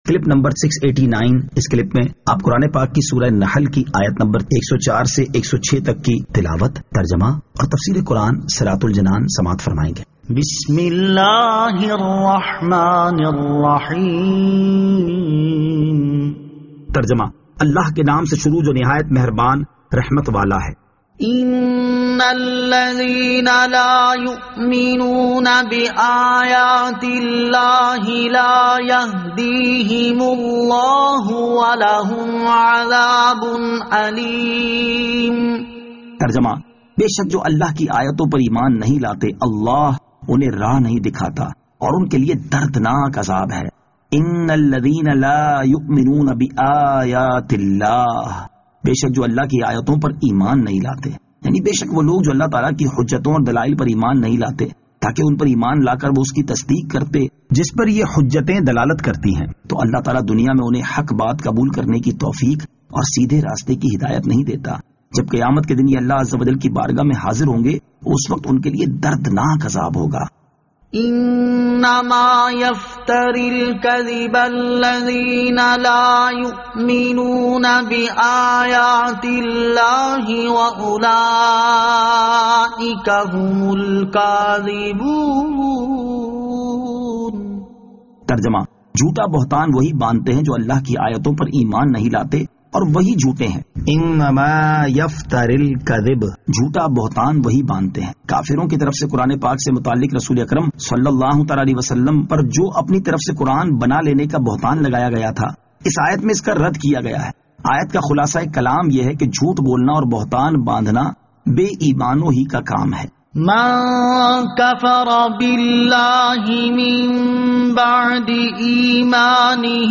Surah An-Nahl Ayat 104 To 106 Tilawat , Tarjama , Tafseer